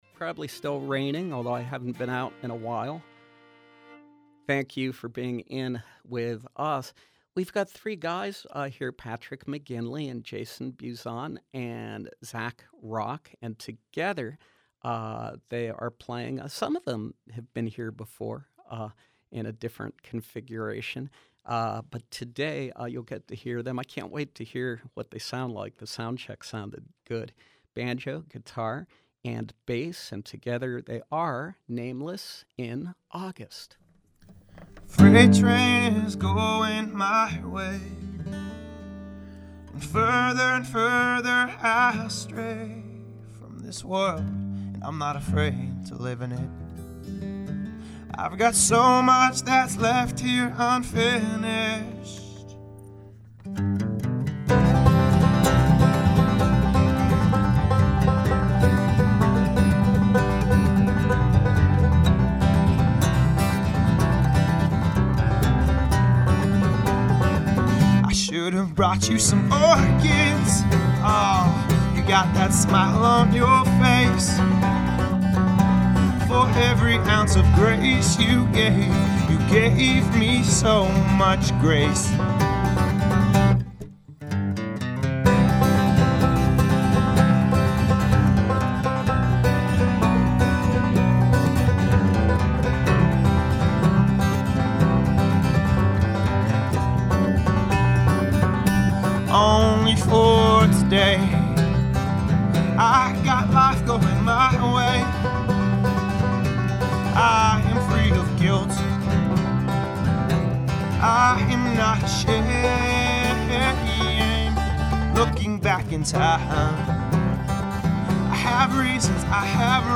folk-rock songs on banjo, acoustic guitar and bass.